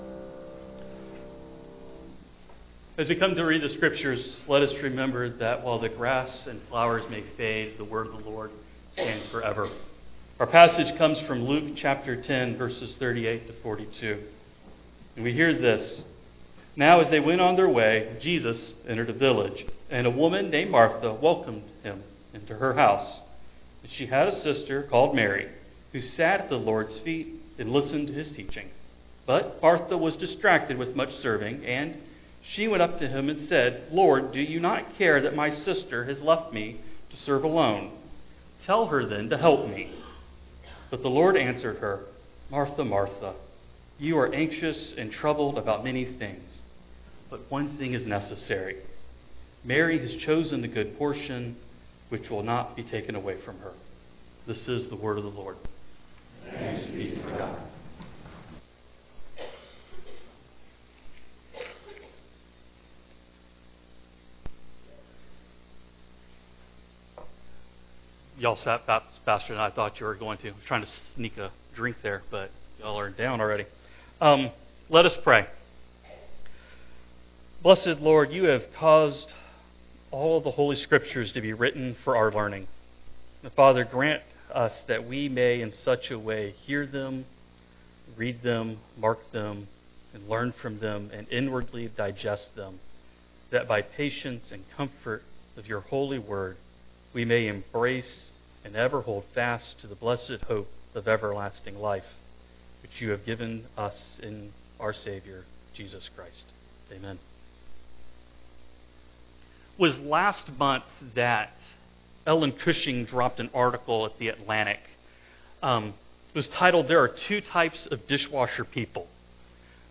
Text for Sermon: II Samuel 21:1-14; John 19:235-25